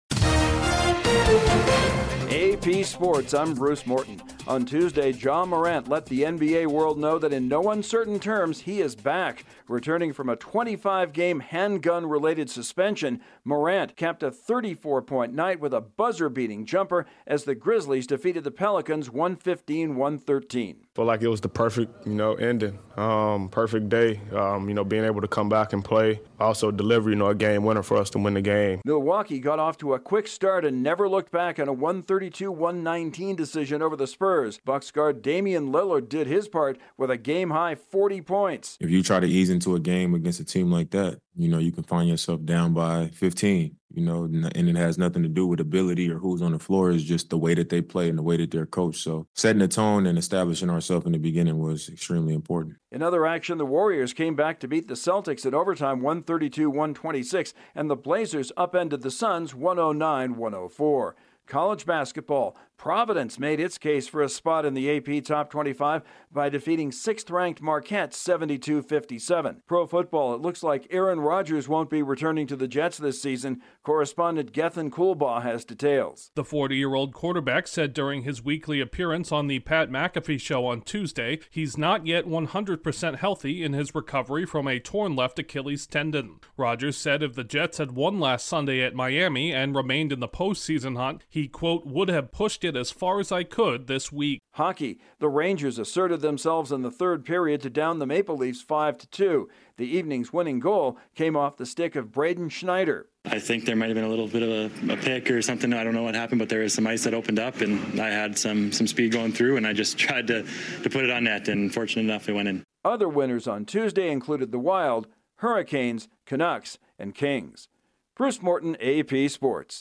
Ja Morant makes his season debut, Providence knocks off Marquette, Aaron Rodgers won't be returning to the Jets this season and the Rangers top the Maple Leafs. Correspondent